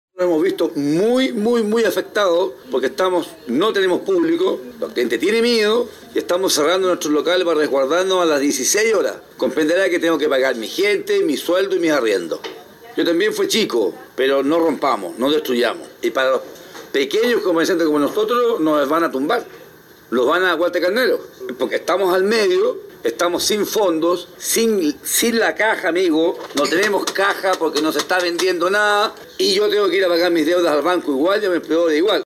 Uno de los empleadores del centro comentó que los locales comerciales están cerrando a las 16:00 horas, ya que durante las manifestaciones son los más afectados estando en el centro de los enfrentamientos.